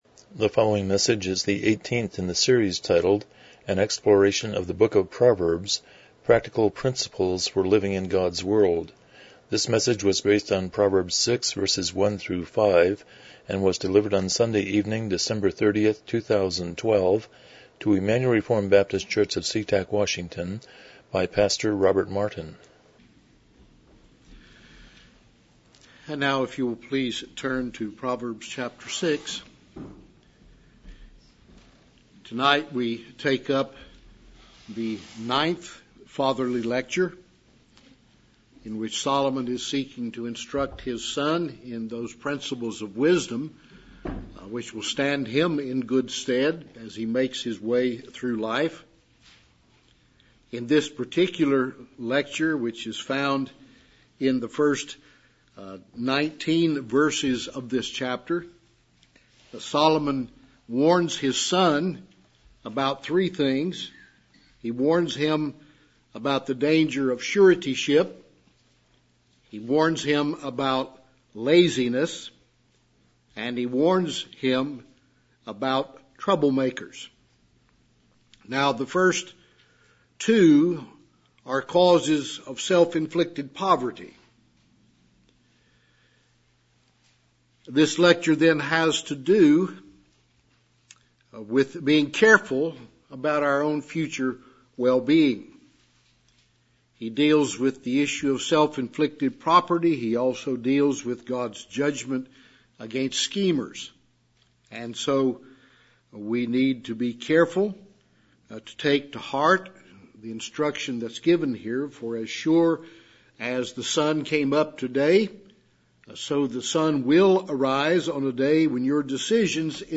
Proverbs 6:1-5 Service Type: Evening Worship « 21 The Sermon on the Mount